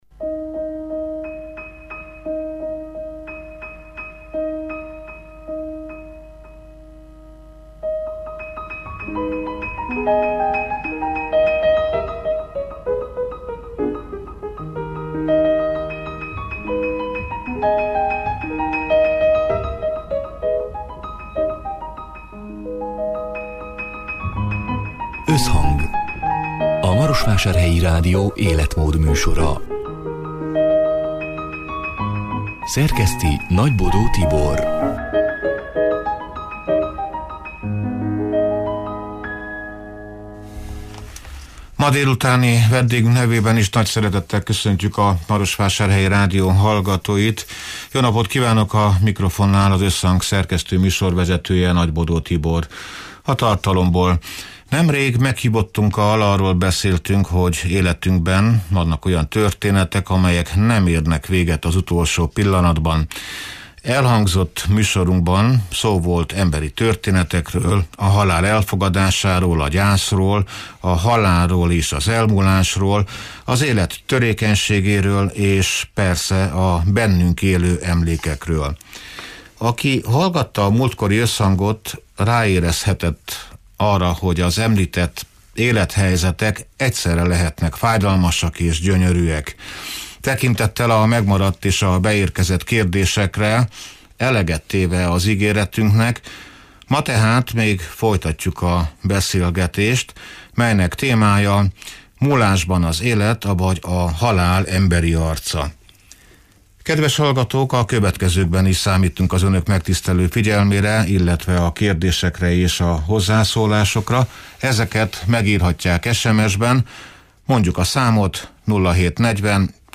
(elhangzott: 2025. június 11-én, szerdán délután hat órától élőben)